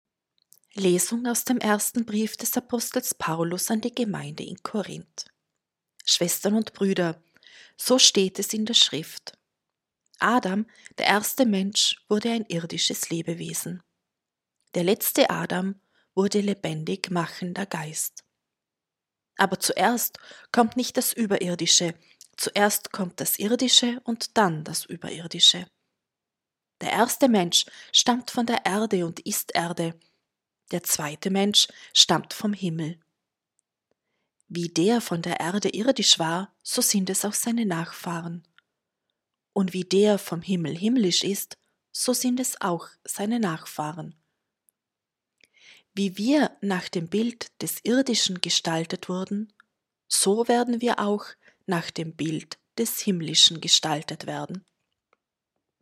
Wenn Sie den Text der 2. Lesung aus dem ersten Brief des Apostel Paulus an die Gemeinde in Korínth anhören möchten: